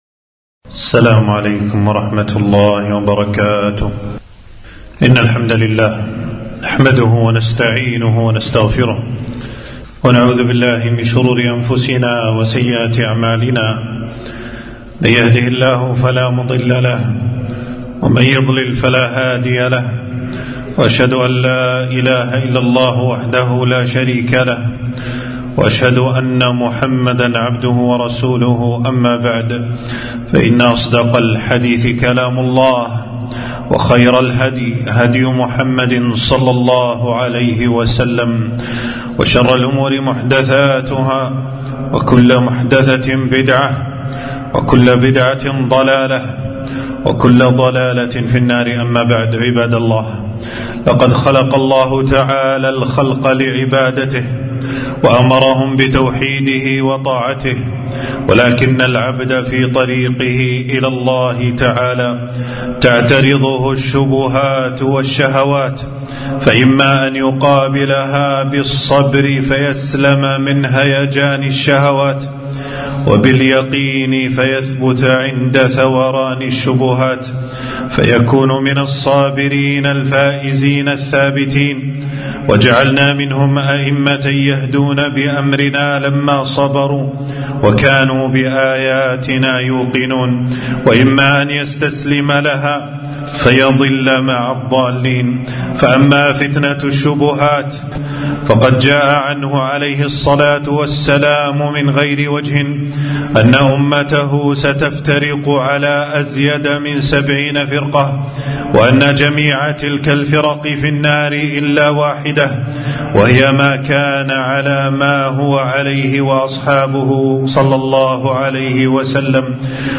خطبة - غربة أهل السنة